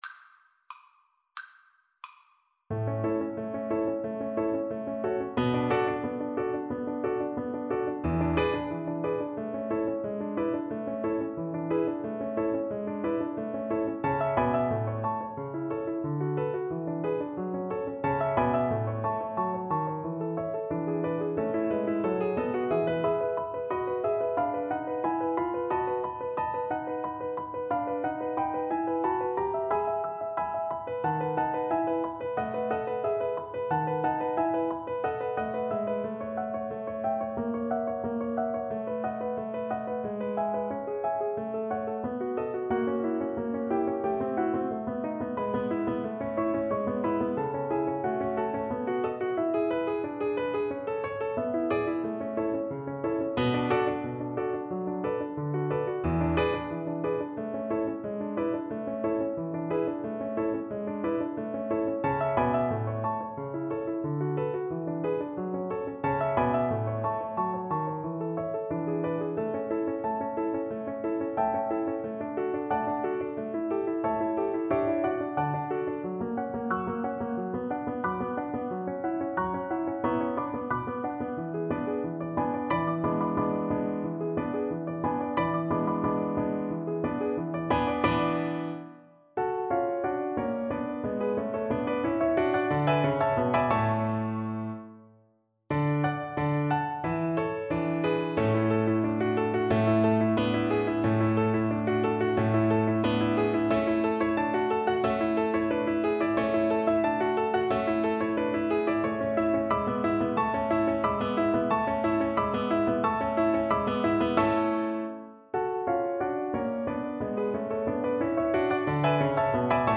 Allegro vivace =90 (View more music marked Allegro)
2/2 (View more 2/2 Music)
Classical (View more Classical Viola Music)